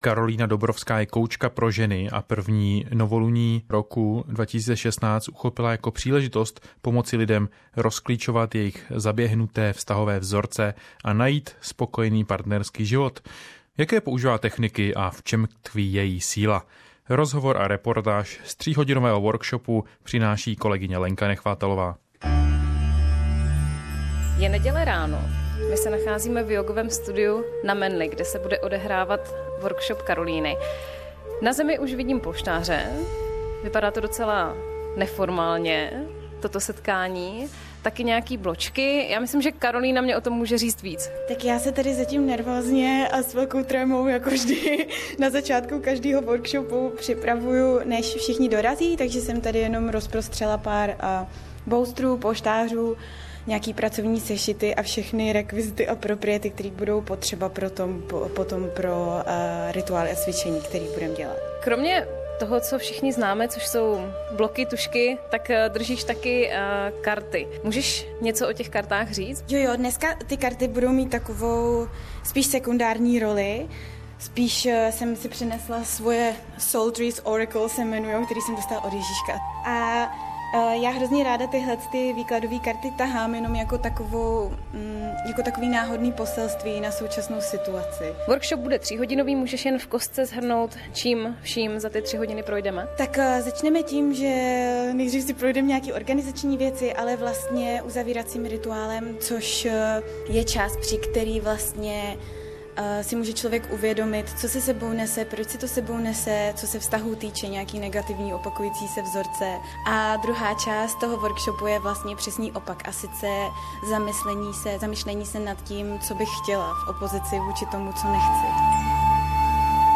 Jaké používá techniky a v čem tkví její síla? Rozhovor a reportáž z tříhodinového workshopu